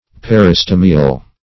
Peristomial \Per`i*sto"mi*al\, a.